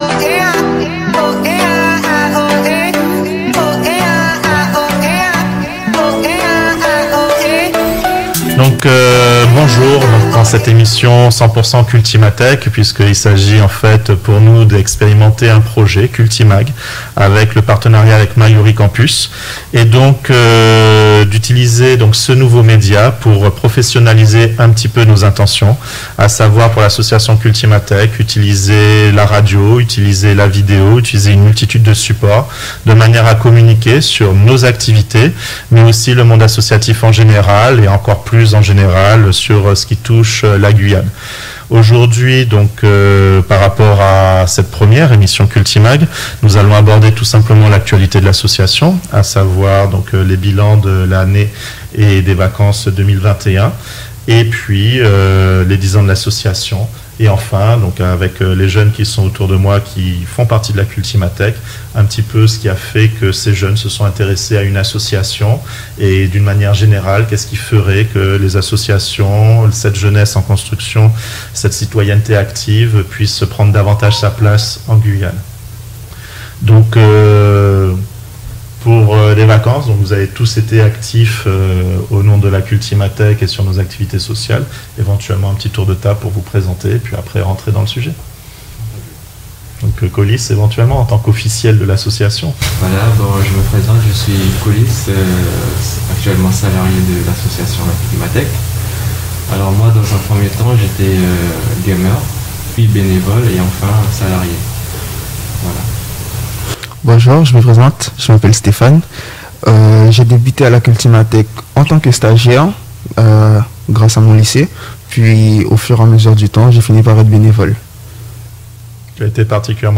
CultiMag est un nouveau programme porté par la Cultimathèque en partenariat avec Radio Mayouri Campus. Dans cette émission, diffusée, chaque mercredi à 16 heures, à partir de ce mercredi 15 septembre, des jeunes bénévoles parlent de leur engagement associatif.